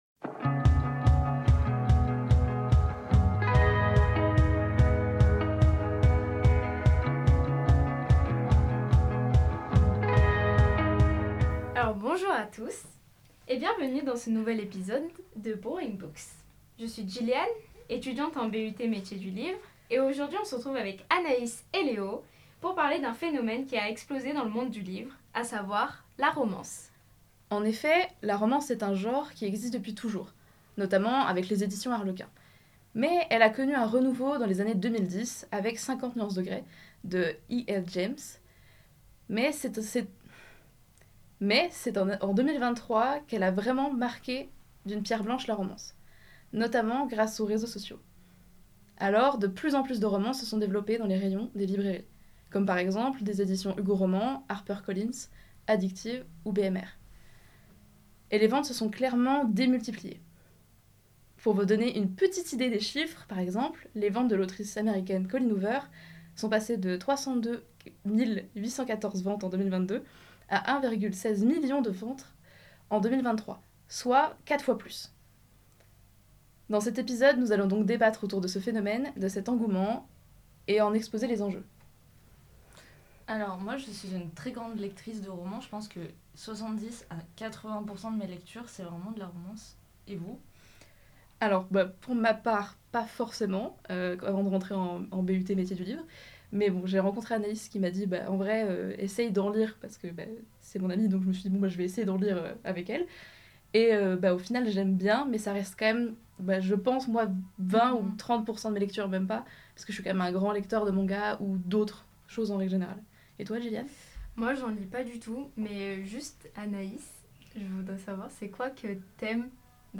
Dans ce nouvel épisode de Brewing Books, nous vous proposons un débat sur ce genre qui a véritablement explosé en 2023.